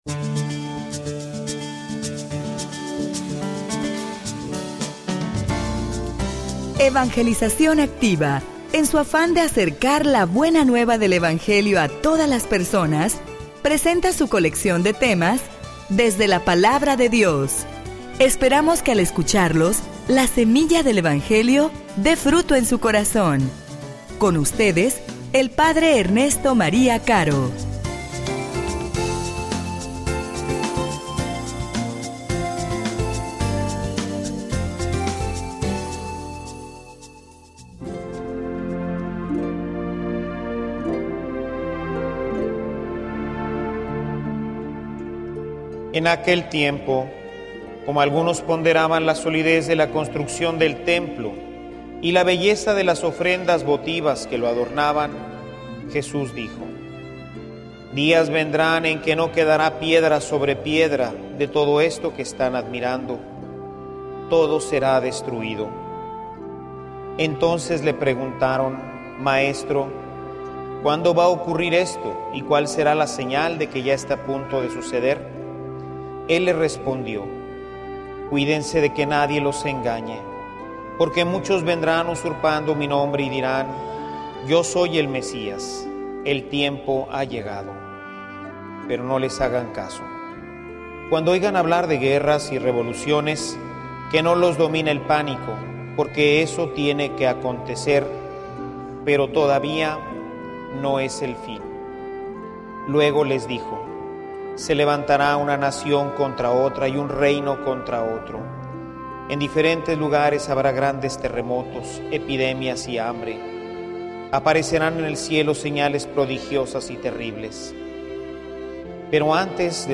homilia_Testigos_de_la_Verdad.mp3